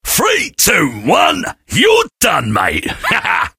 sam_kill_vo_02.ogg